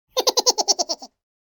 Silly-cartoon-character-laughing-sound-effect.mp3